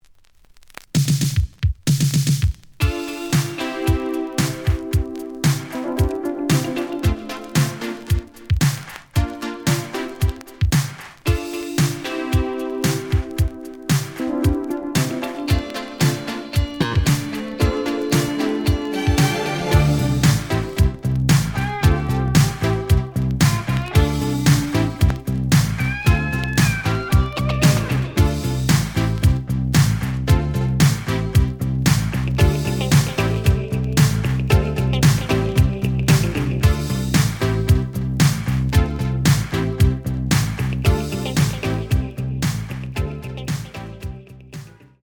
(Instrumental)
The audio sample is recorded from the actual item.
●Genre: Disco